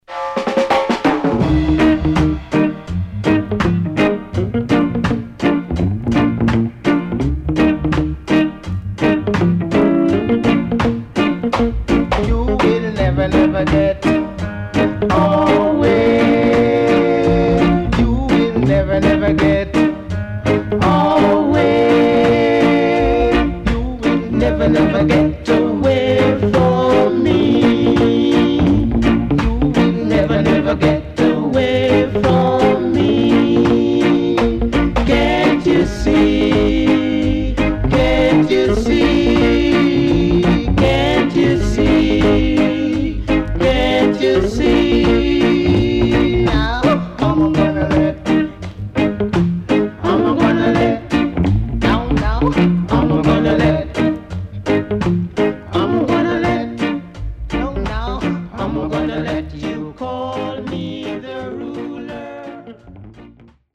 HOME > REISSUE [SKA / ROCKSTEADY]